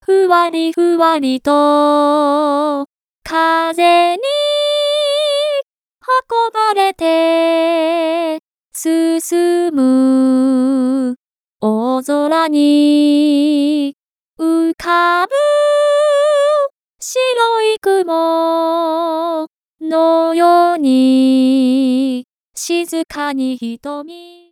これは、ギターのスライド奏法の様に音程が階段状に上げたい場合に使用します。
音符分割でのしゃくり上げ